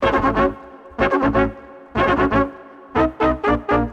ChromaticTrumpets.wav